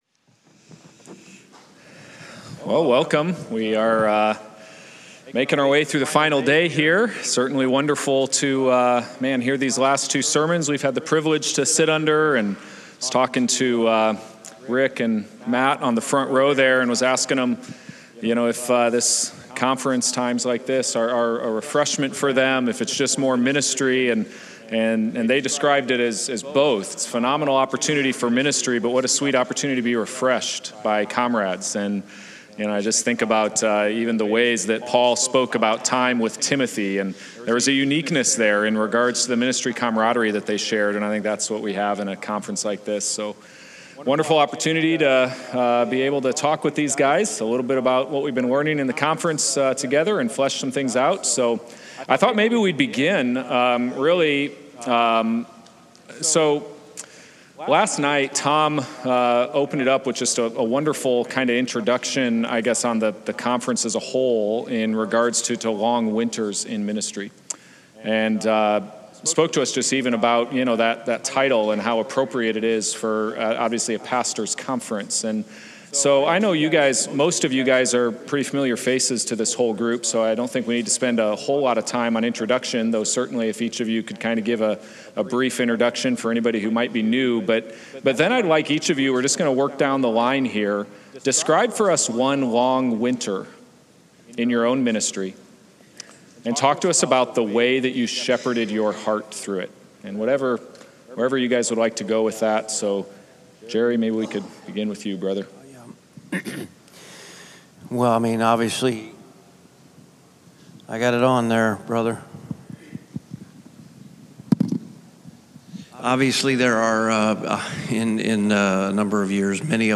Courageous Churchmen 2025 - Forum Discussion 2 from Vimeo.